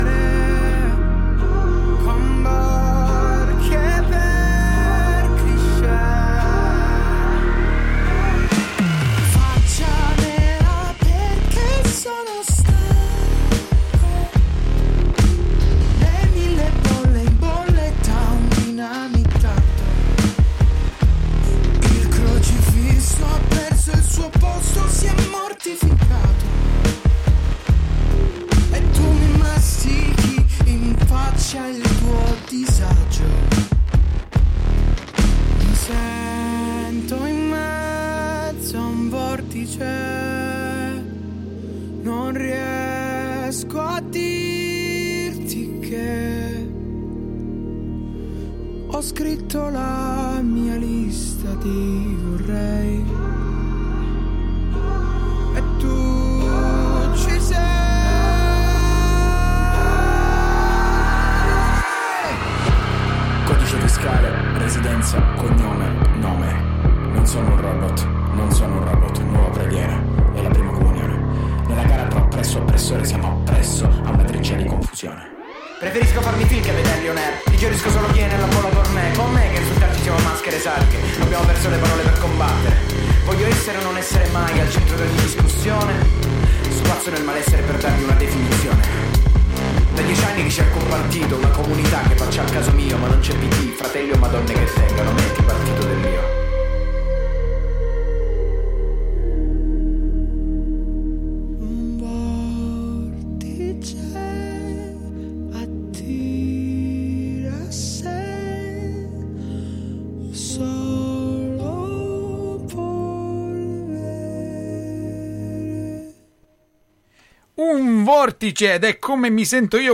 Un' intervista interessante quanto i temi dei suoi testi. Musiche di facile ascolto.